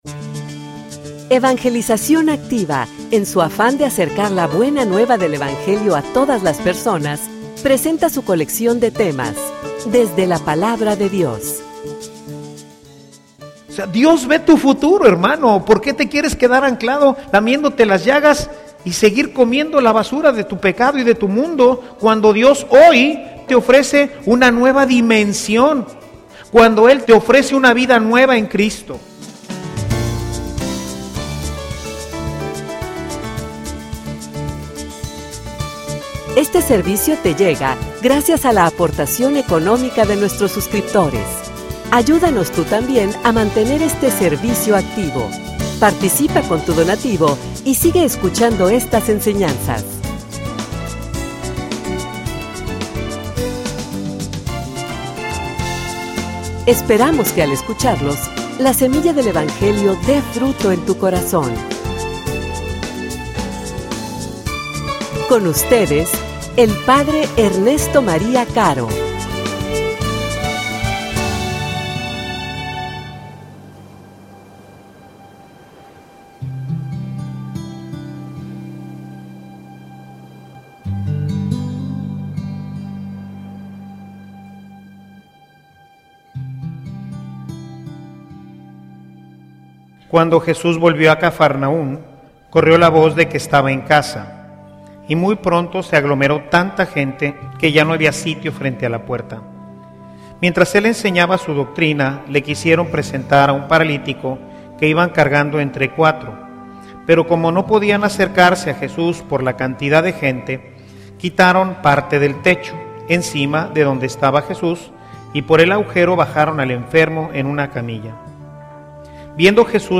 homilia_No_te_ancles_en_tu_pasado.mp3